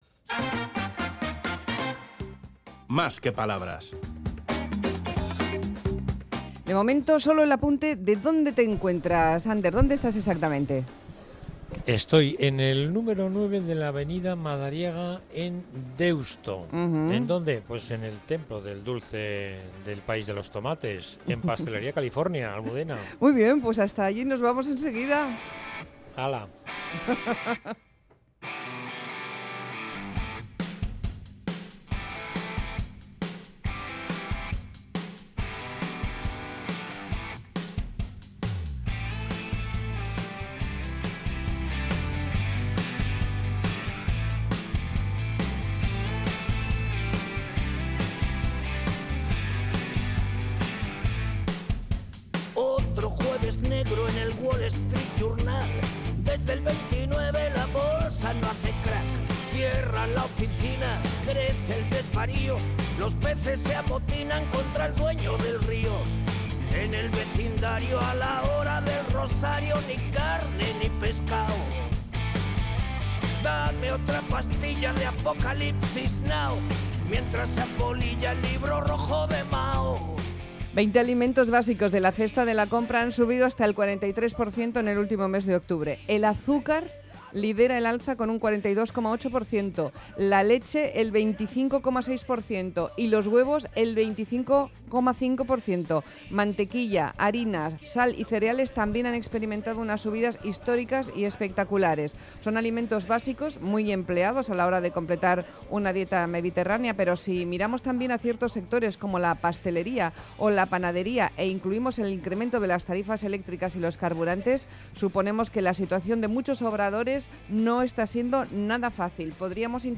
Exterior desde el obrador de la Pastelería California en Deusto